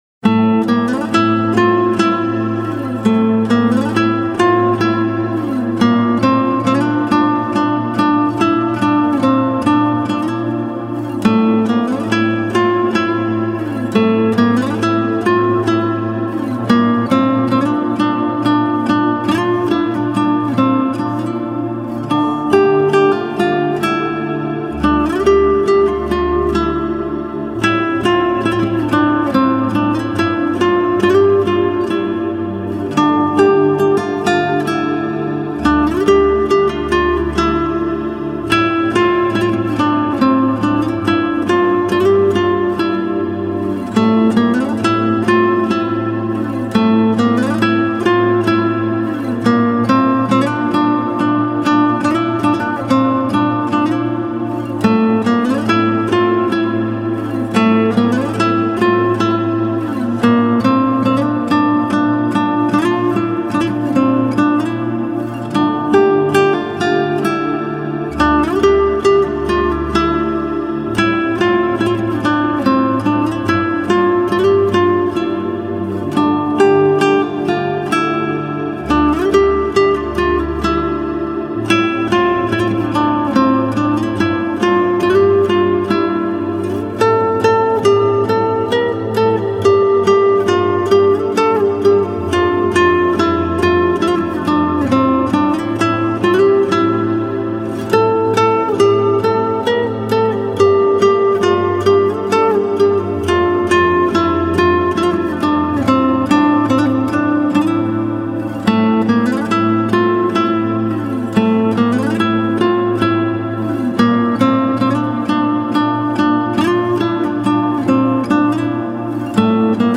آهنگ بیکلام و ملایم برای آخر شب